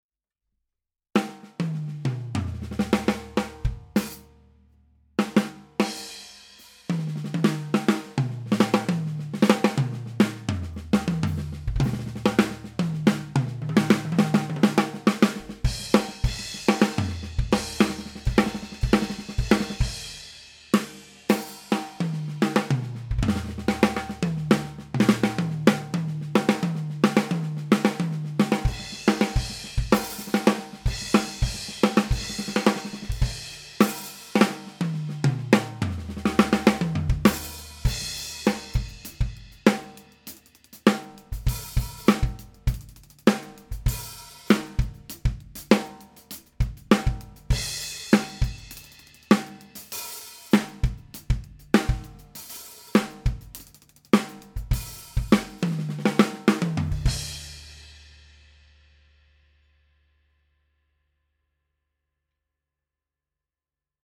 2 x M201TG+e602
Gerade die Feinzeichnung bei den Becken habe ich so noch mit keinem dynamischen Mikrofon gehört und die preiswerten Kondensatormikrofone klingen dagegen immer ein bisschen metallisch und kalt.